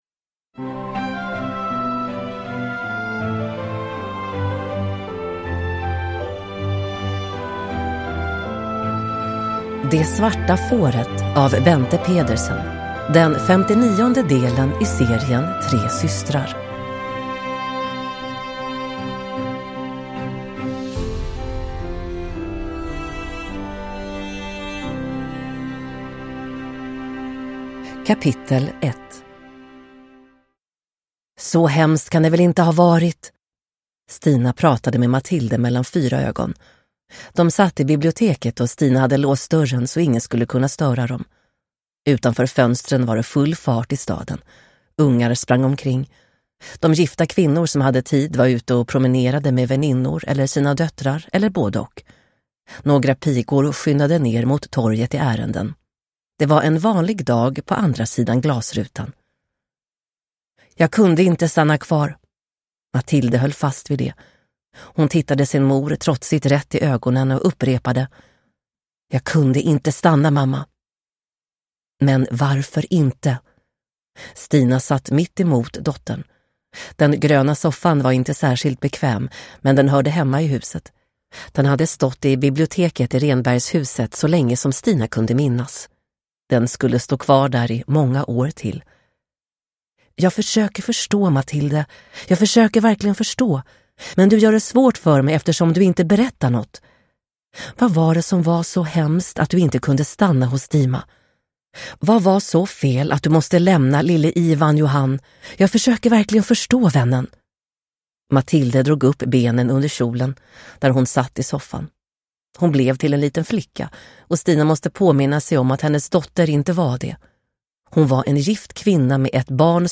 Det svarta fåret – Ljudbok – Laddas ner